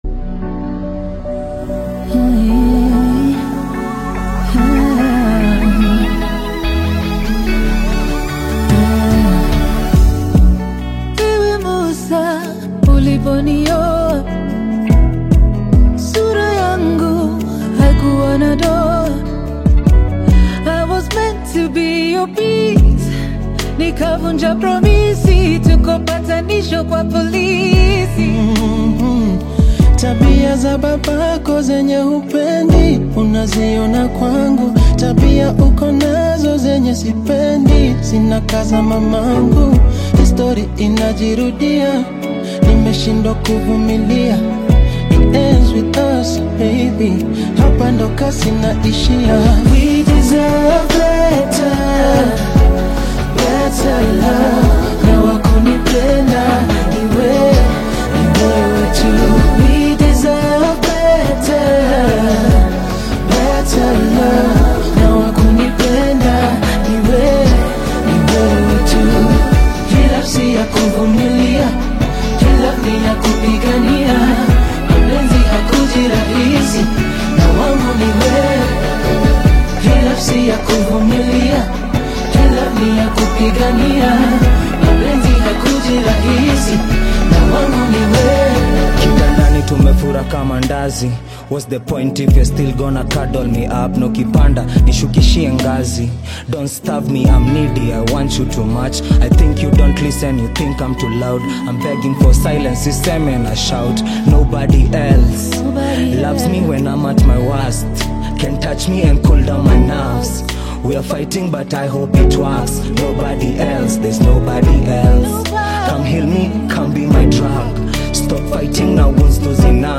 Afro-pop single
Kenya’s contemporary urban sound and melodic interplay